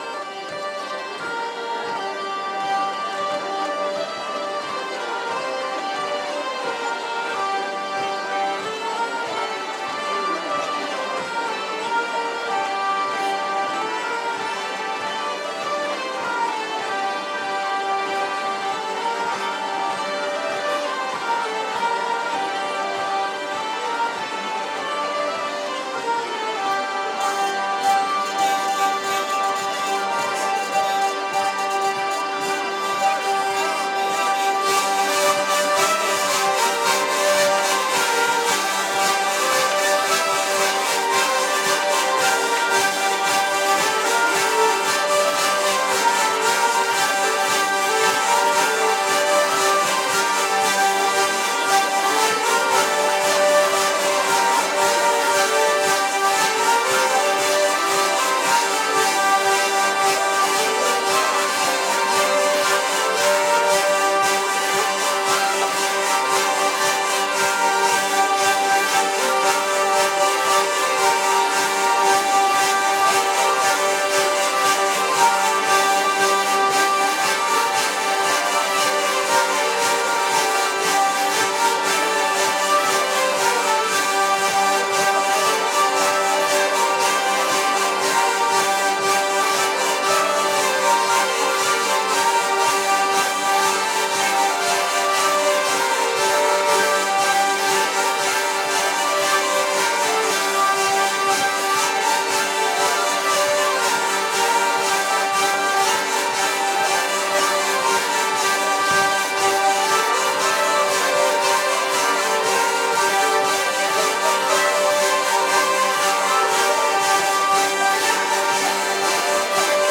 02_bourree_2t-vielles.mp3